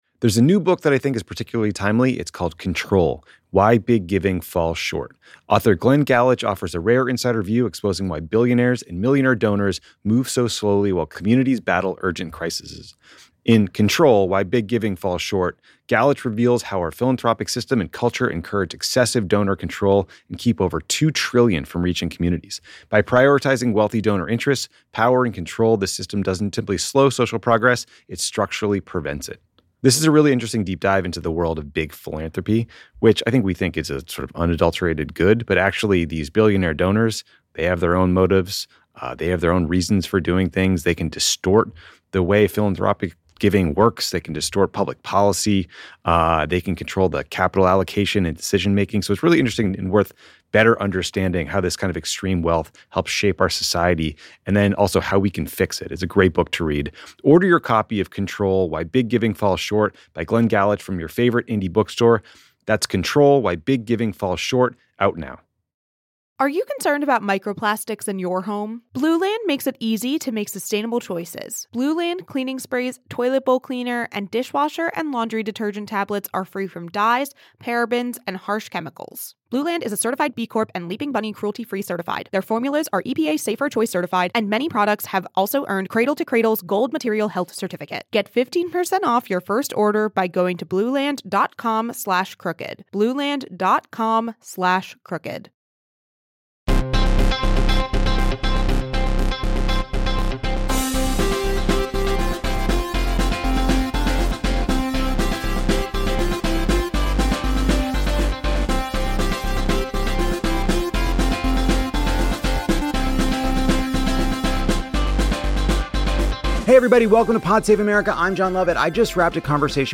Senator Cory Booker stops by the studio to talk to Lovett about his bold tax proposal that would see the majority of Americans pay no federal income tax. Jon and the senator debate what it means when both political parties become anti-tax and discuss Trump's war with Iran, AIPAC's role in the Democratic Party, and the president's sudden firing of Attorney General Pam Bondi.